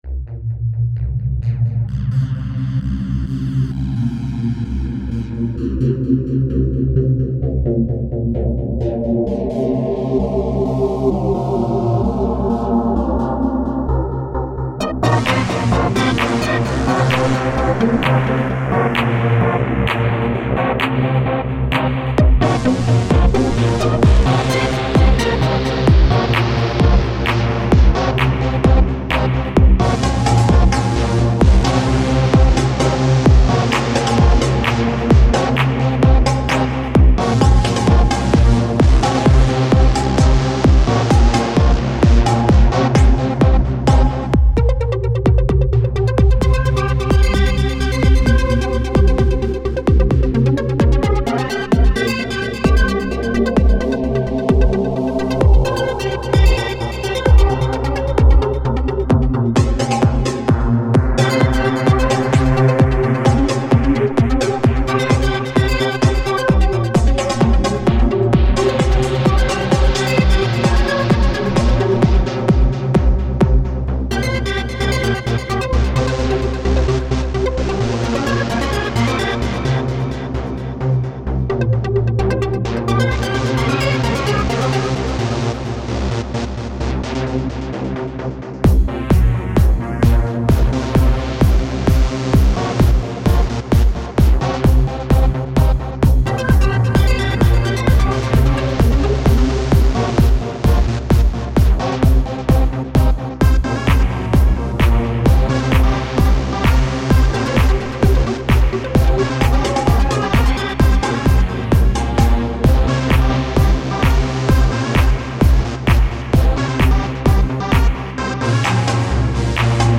Techno
Trance
Electro